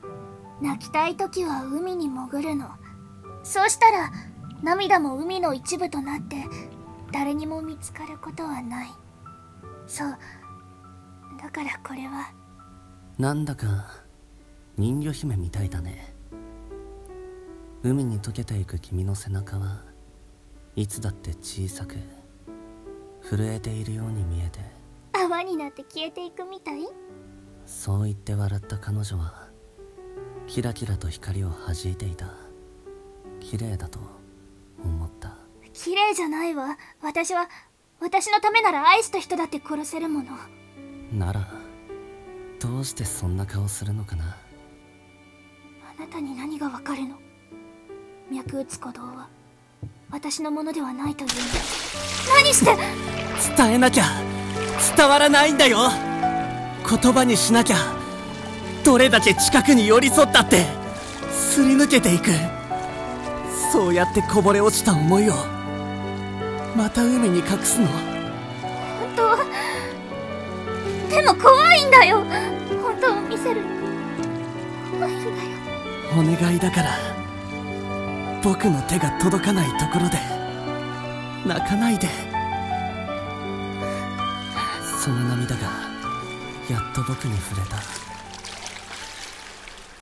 二人声劇】人魚姫のメーデー